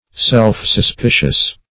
Search Result for " self-suspicious" : The Collaborative International Dictionary of English v.0.48: Self-suspicious \Self`-sus*pi"cious\, a. Suspicious or distrustful of one's self.